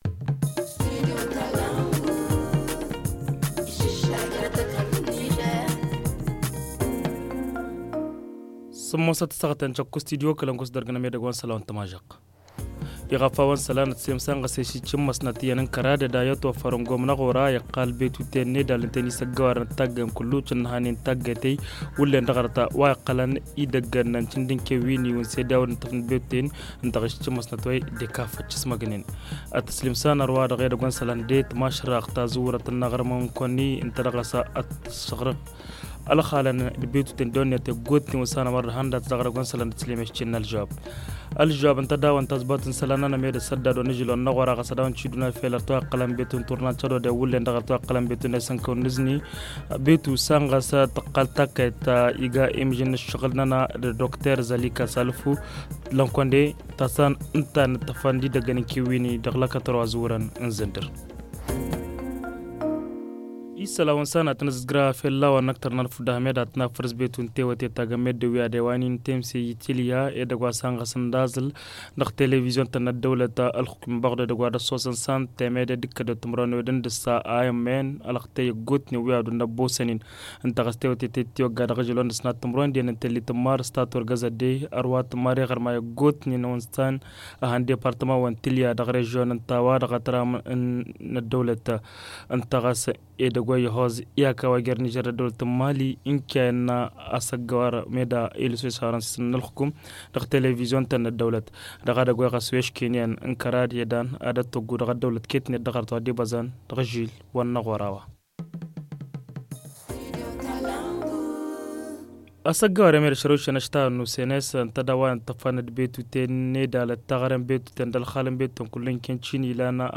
Le journal du 23 mars 2021 - Studio Kalangou - Au rythme du Niger